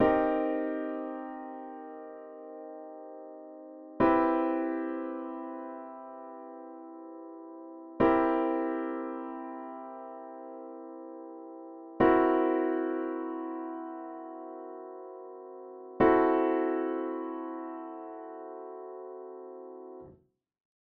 In classical music, there are five basic seventh chord types: minor sevenths, major sevenths, dominant sevenths, half-diminished sevenths, and diminished seventh chords.
Examples of Seventh Chords